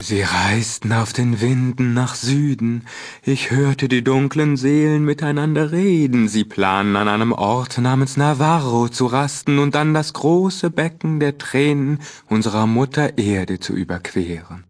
Fallout 2: Audiodialoge